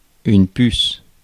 Ääntäminen
IPA : /fliː/